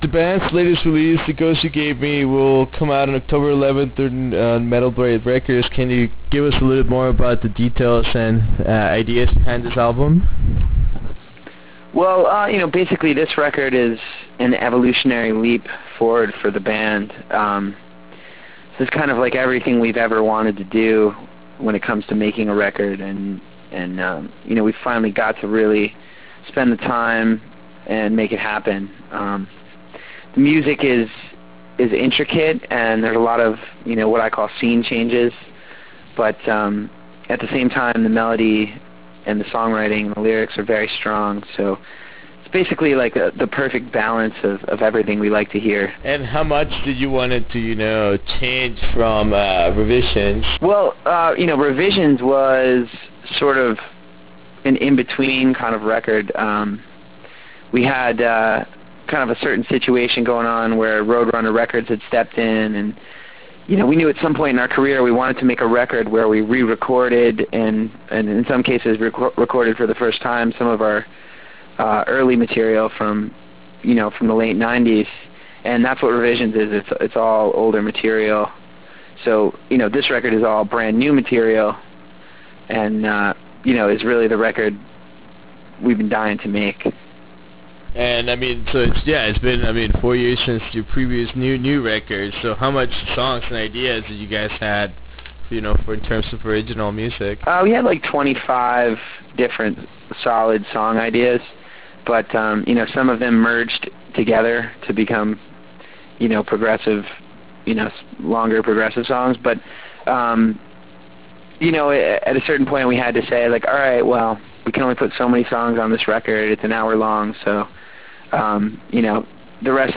In this interview we talk about the new release and how has the band evolved over time. We also discuss the band’s future touring plans and what is next in the path of such a talented band.